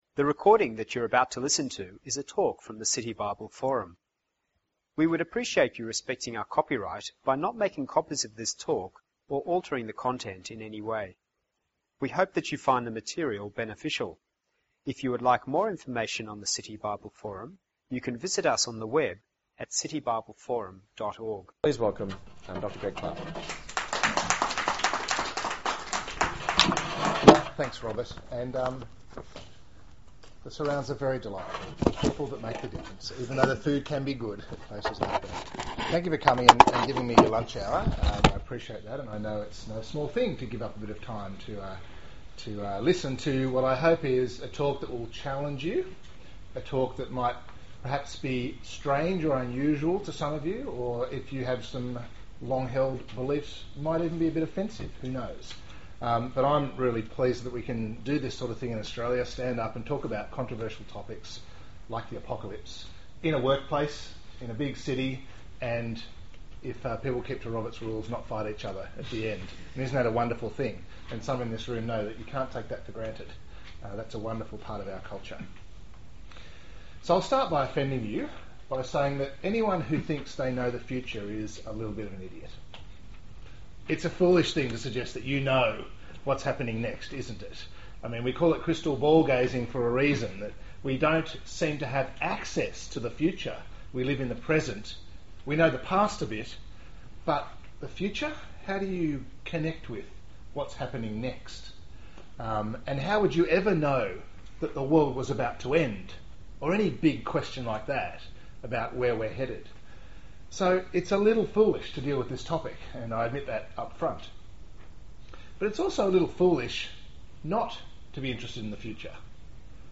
presents in a lunchtime forum on 'It's the end of the world